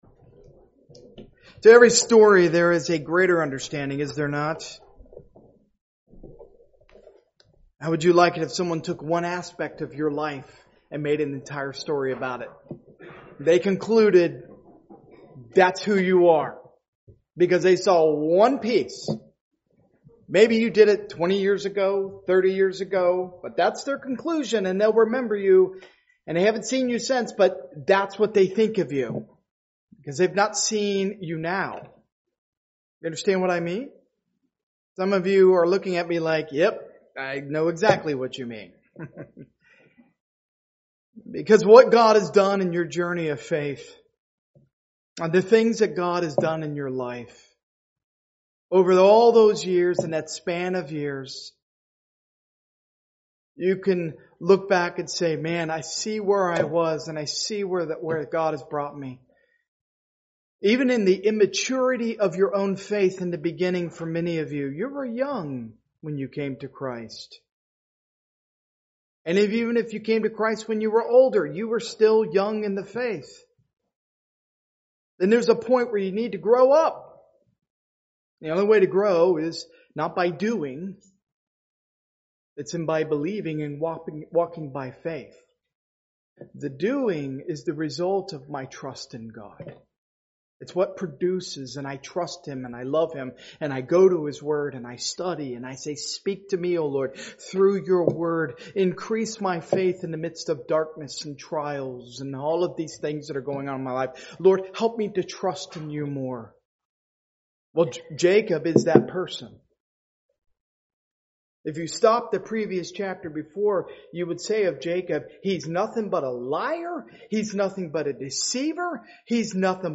Part of the The Book of Genesis series, preached at a Morning Service service.